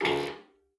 Light tubes now make a noise when turning on.
lighton.ogg